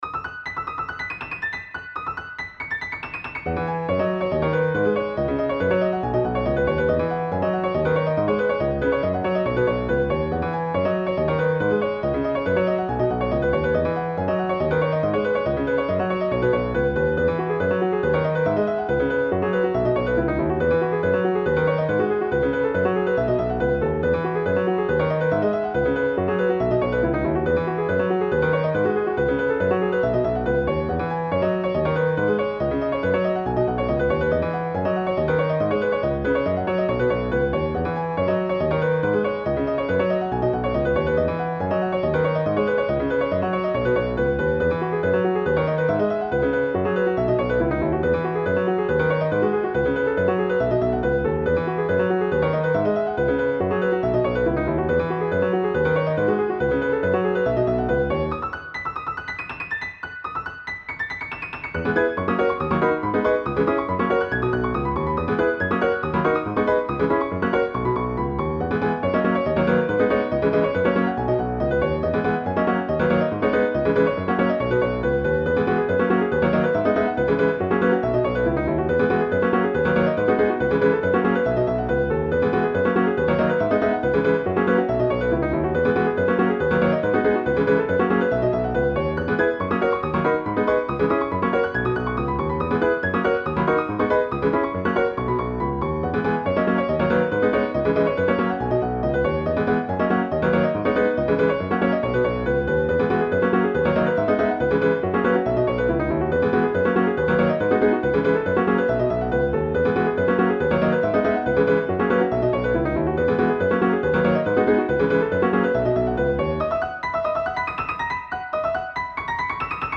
para hacer variaciones y ligeros cambios de leitmotiv